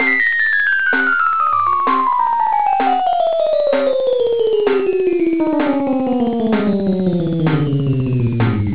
The Y axis of the data point is represented by the pitch of the piano instrument. The first and second derivatives of the data are represented with the drum beat. The frequency of the beat represents the slope (so the greater the slope, the more frequent the beat.) The second derivative is represented by the pitch of the drum beat, a low pitch indicates positive curvature (concave) and a high pitch indicates negative curvature (convex); Linear data is indicted with a medium pitch.